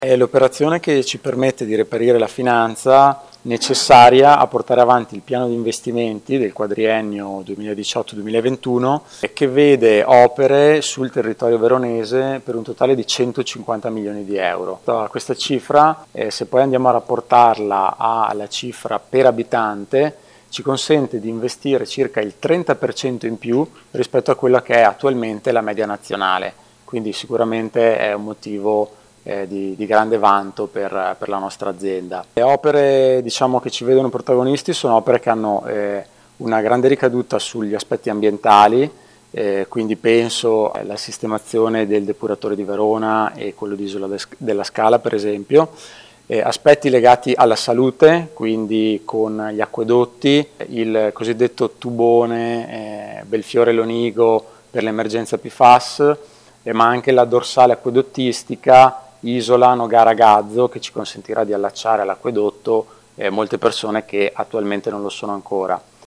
Si è tenuta nella “Torre Diamante” di Milano la conferenza stampa relativa al finanziamento da 60 milioni di euro a supporto di Acque Veronesi.